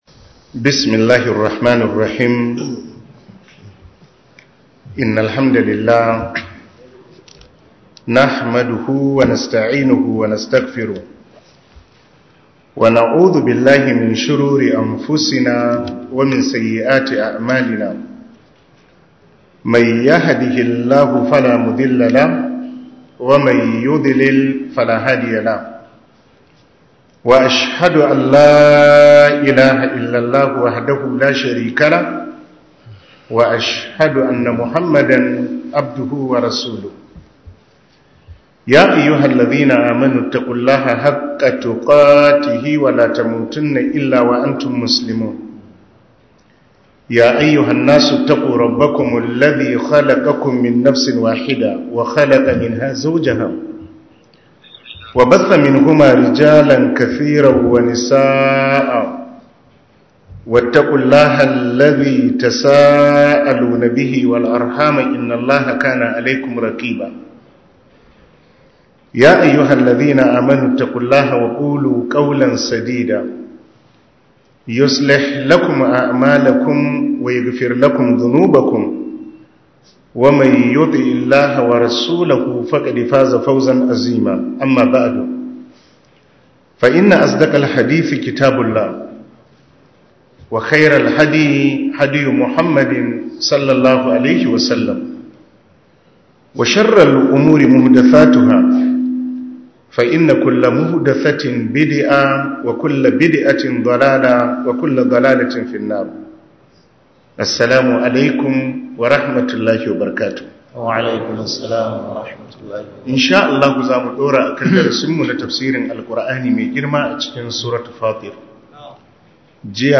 14 Ramadan Tafsir